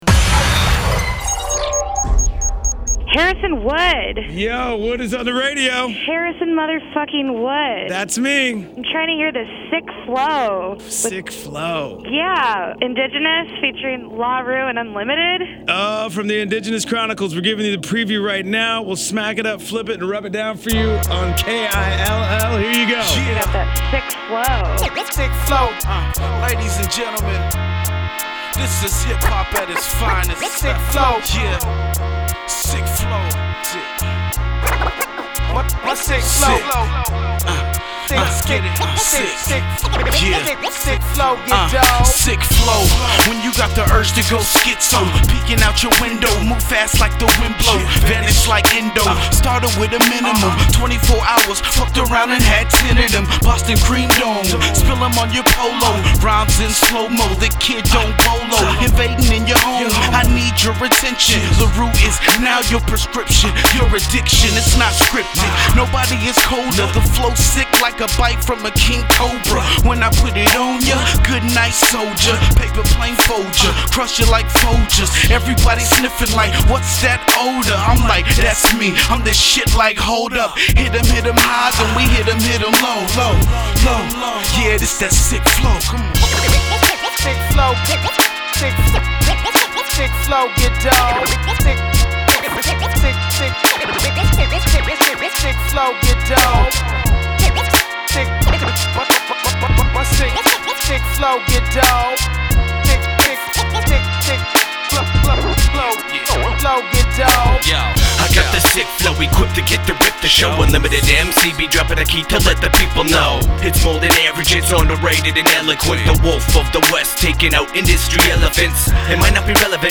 hip hop
Recorded at Ground Zero Studios